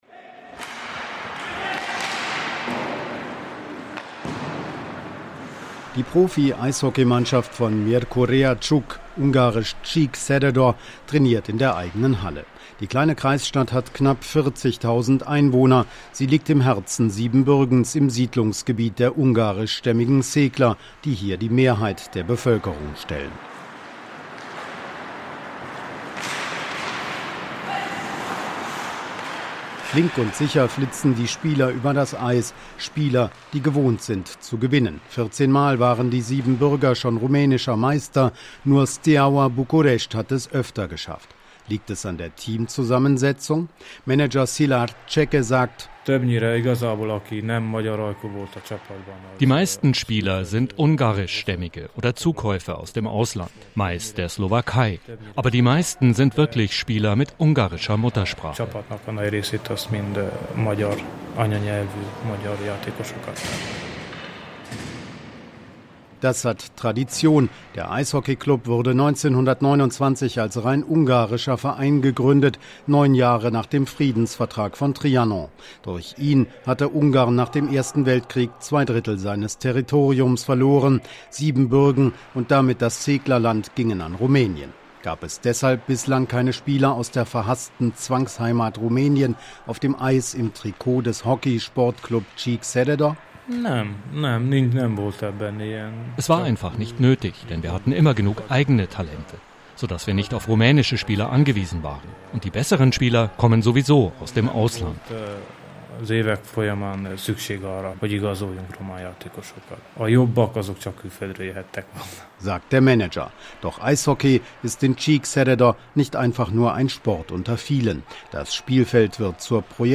Ein Feature
Feature-Ein-Volk-zwischen-den-Stühlen-bei-den-Szeklern-in-Siebenbürgen.mp3